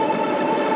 drum-spinnerspin.wav